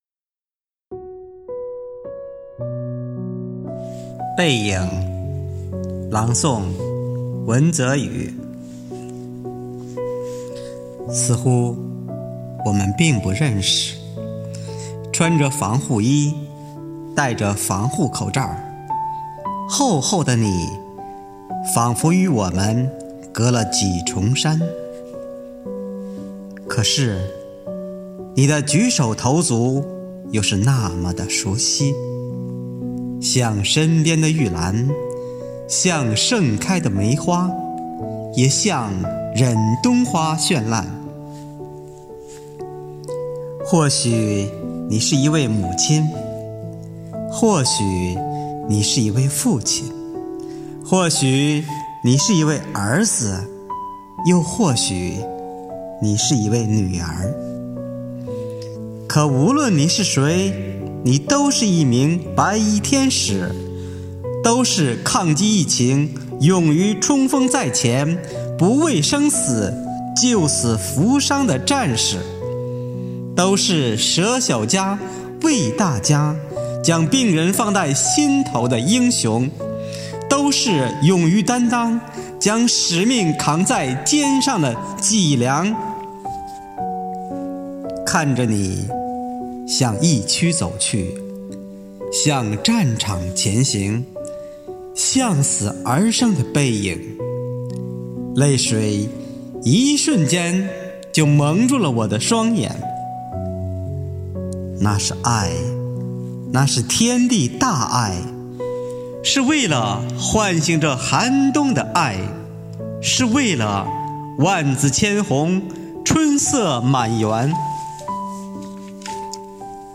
为声援战斗在一线的工作人员，鼓舞全区人民抗击疫情的信心和决心，丰南文化馆、百花艺术团、丰南诗歌与朗诵协会组织诗歌与诵读工作者、爱好者共同创作录制诵读作品。